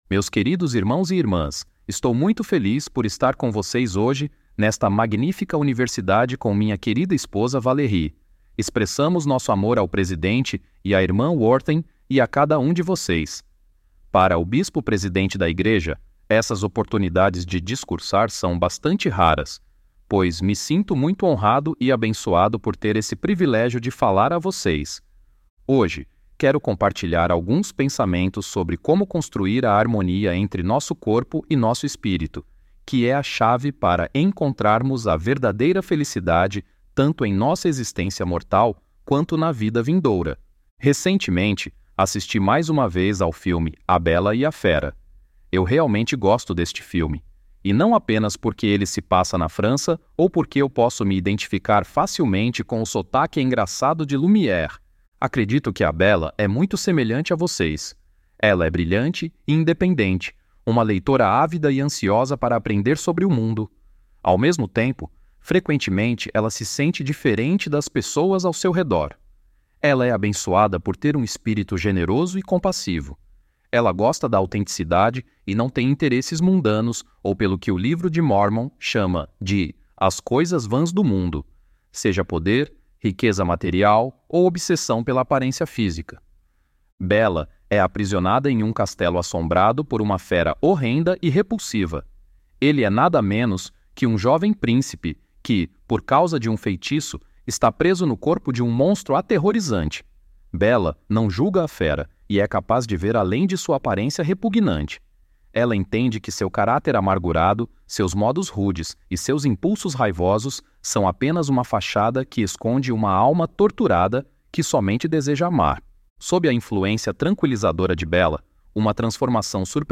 Bispo presidente de A Igreja de Jesus Cristo dos Santos dos Últimos Dias